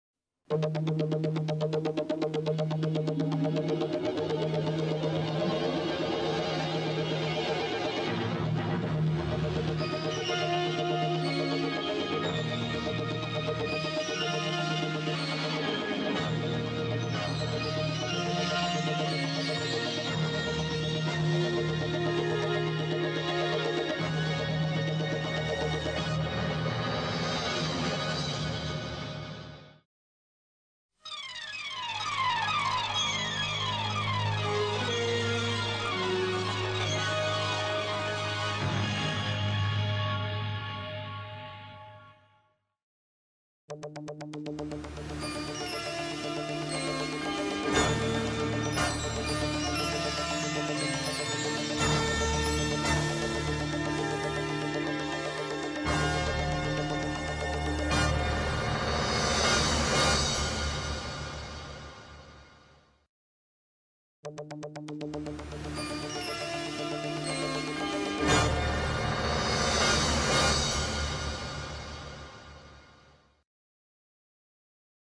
είναι τα σήματα ειδήσεων